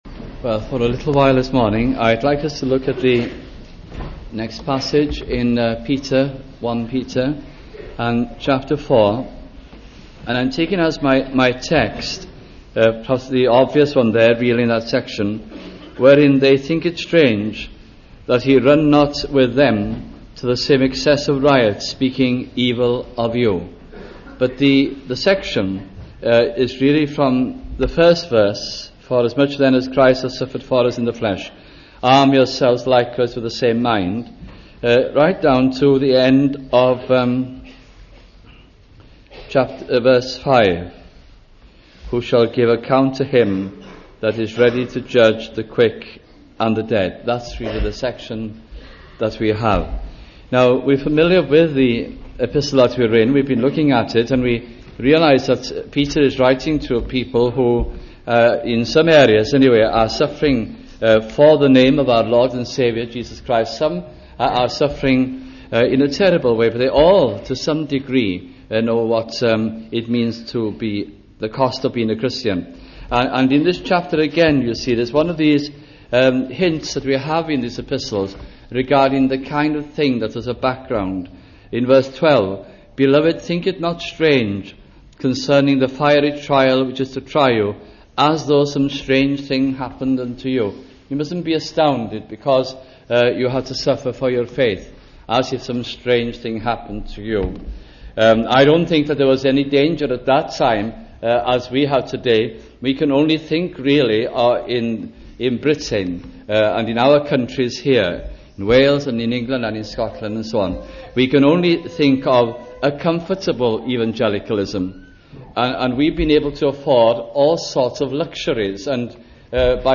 » First Epistle of Peter Series 1982 - 1983 » sunday morning messages from this gracious epistle